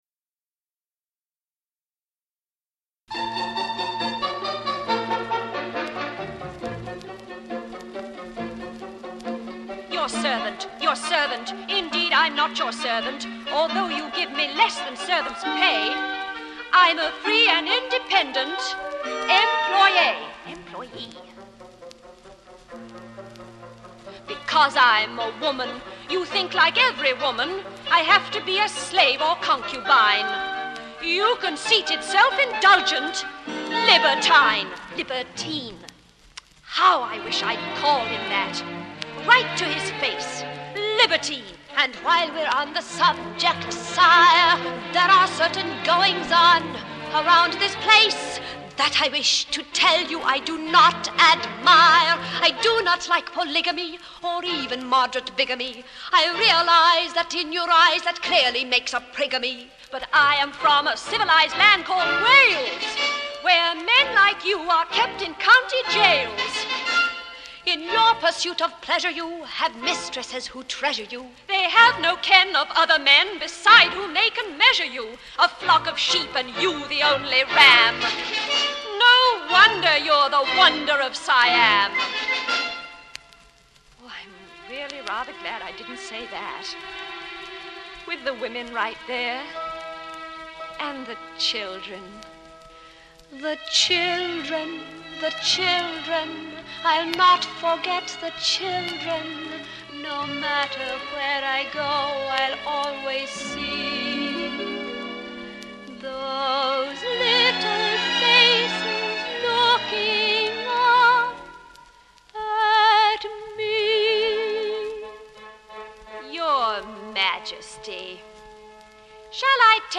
HIGH FIDELITY STEREOPHONIC RECORDING